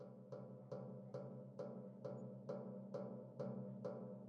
电风扇金属烤架样品 " 电风扇烤架 打3
描述：电风扇作为打击乐器。击打和刮擦电风扇的金属格栅可以发出美妙的声音。
Tag: 金属的 混响 电动风扇 样品